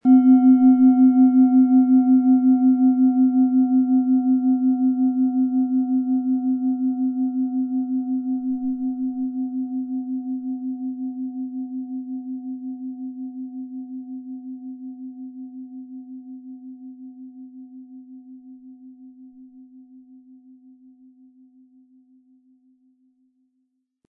Antike Klangschalen – gesammelte Unikate
Die Oberfläche zeigt sanfte Spuren der Zeit – doch ihr Klang bleibt rein und klar.
• Begleitton: DNA (528 Hz)
Der Klang dieser Schale vereint inspirierende Weite mit sanfter Herzenswärme. Beim Anschlagen entfaltet sich eine tragende Resonanz, die zugleich belebt und beruhigt.
MaterialBronze